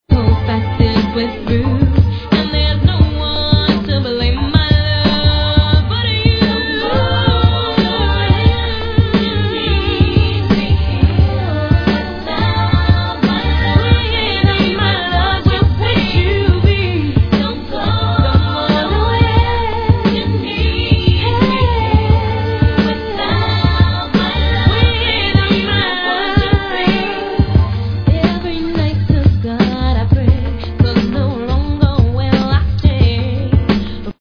90'S R&B Classics!!
Tag       CLASICCS R&B